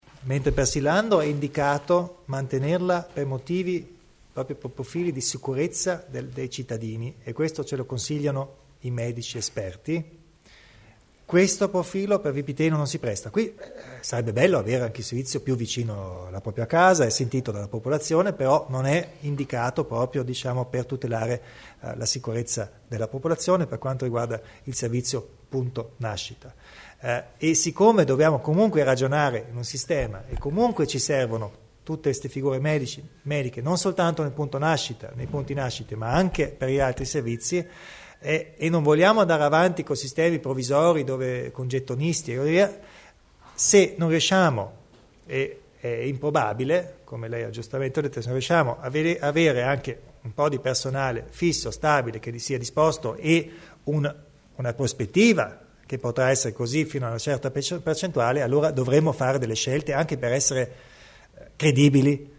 L'Assessore Stocker illustra le strategie in tema di personale per i punti nascita